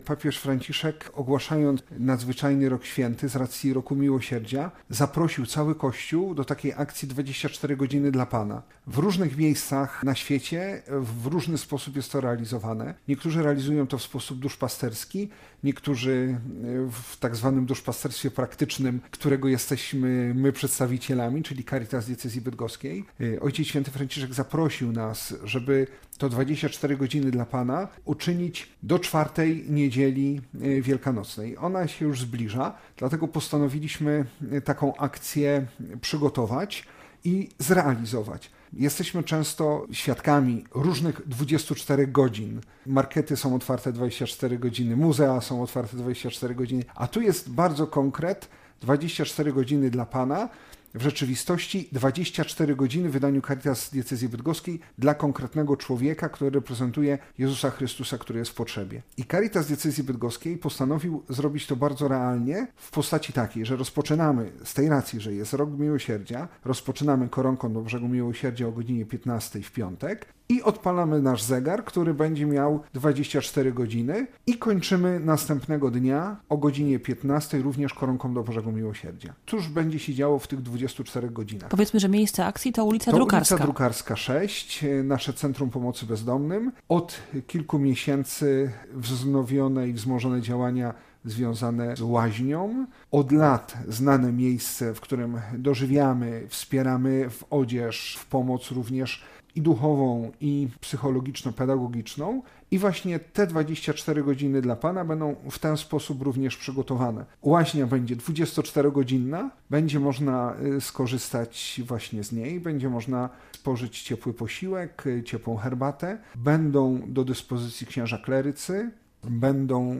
Zachęcamy do wysłuchania audycji radiowej w Radiu PiK